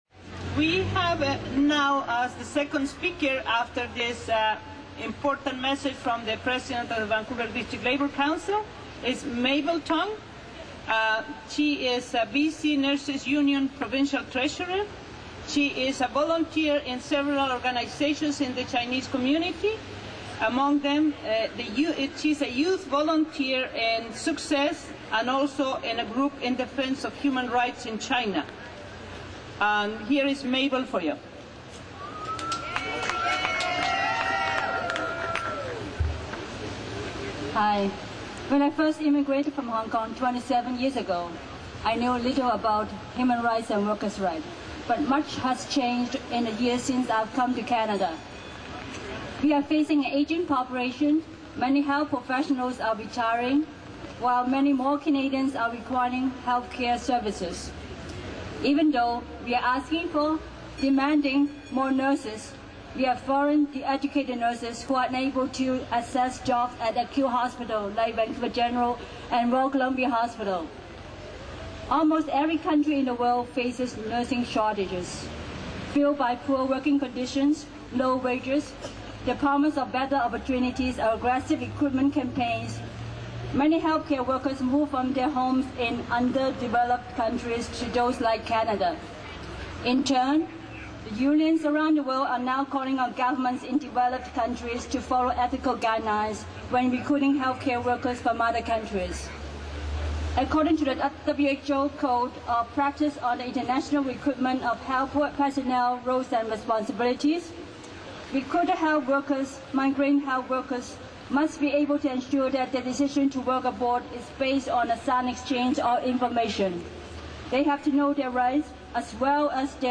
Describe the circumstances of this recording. Webcast highlights from the April 29, 2006 Vancouver and District Labour Council Mayday march and rally. Mayday 2006 rally